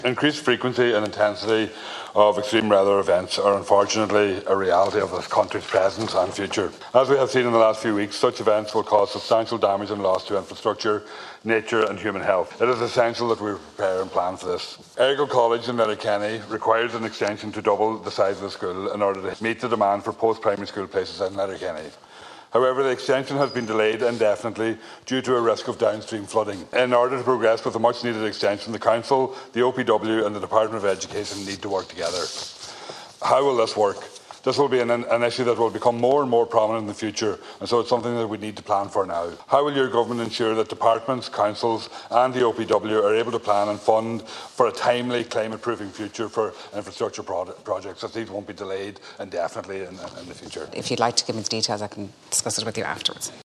Deputy Pringle told the Dail climate-proofing is necessary for infrastructure projects, says the Errigal College expansion has been delayed because of the risk of downstream flooding.